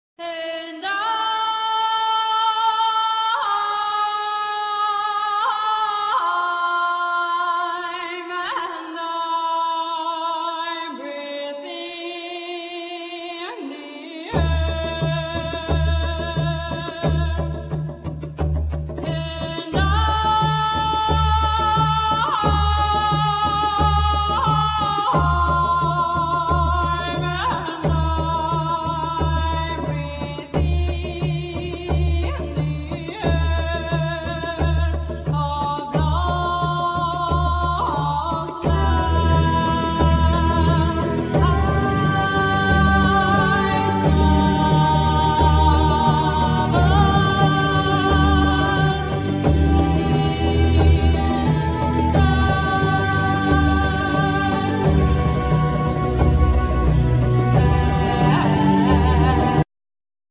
Voice,Keyboards,Percussions
Flute
Violin
Oboe
Guitar
Drums